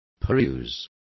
Complete with pronunciation of the translation of perusing.